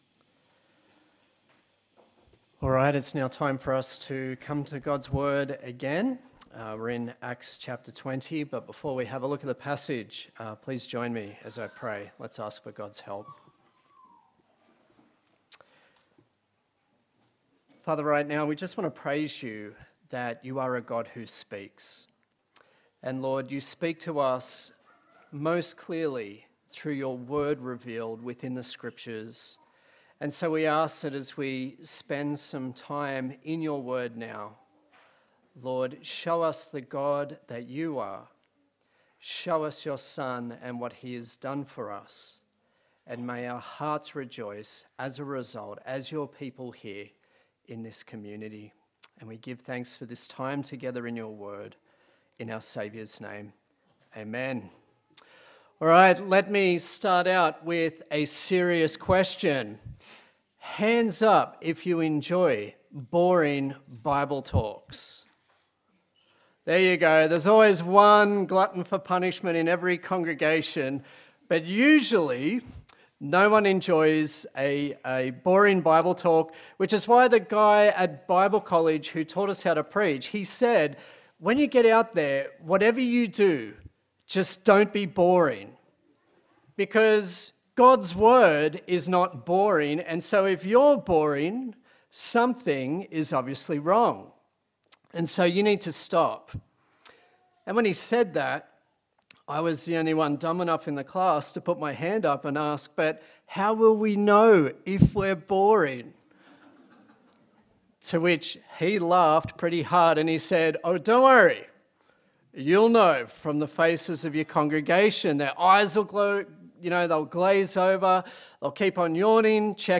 Acts Passage: Acts 20:7-20:38 Service Type: Sunday Service